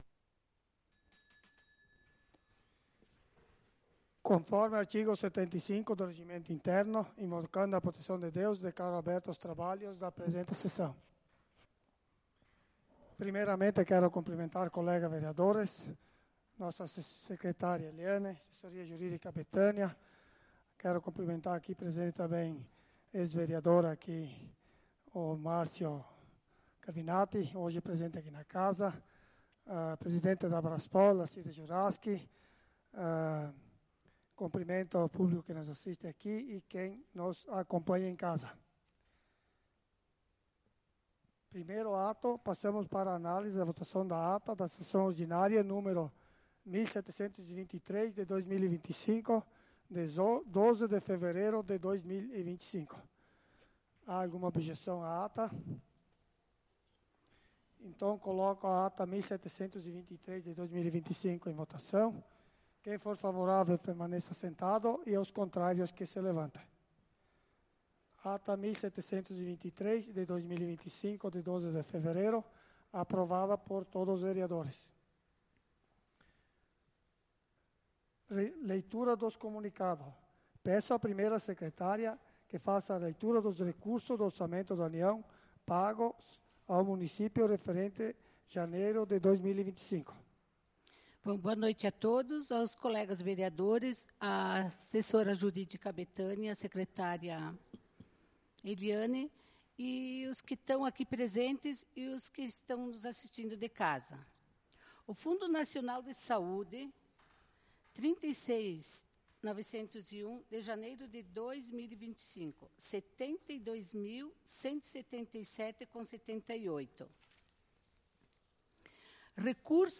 Sessão Ordinária do dia 19/02/2025